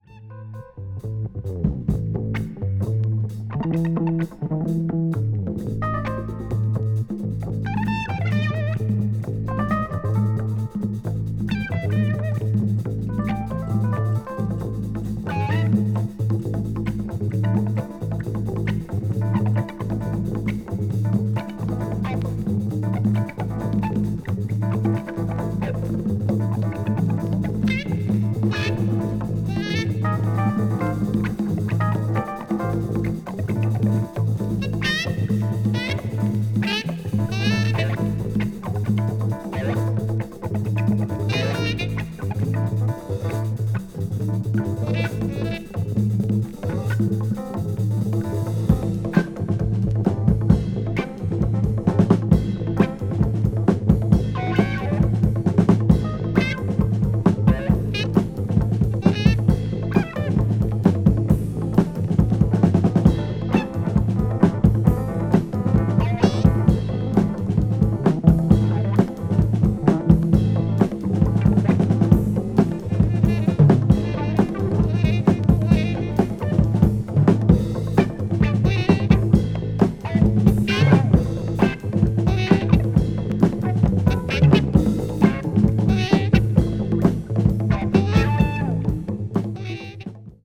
blues rock   crossover   jazz rock   psychedelic rock